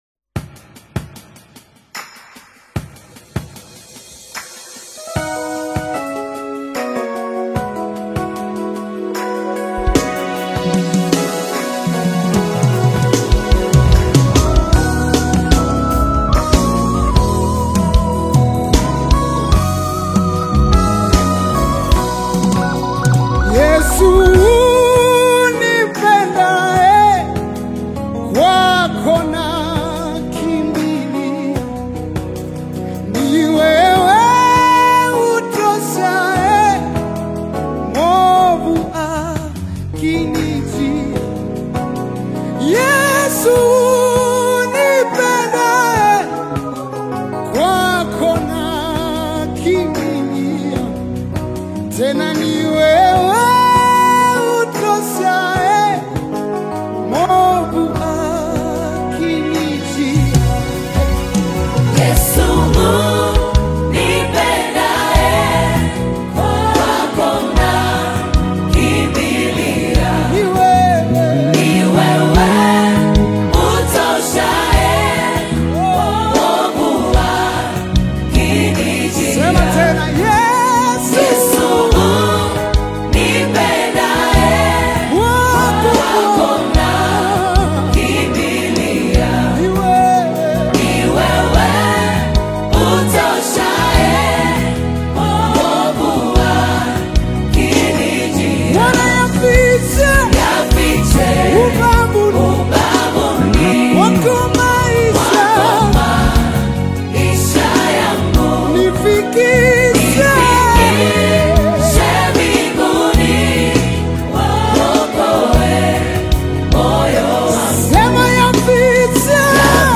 Gospel anthem